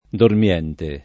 dormiente [ dorm L$ nte ] → dormente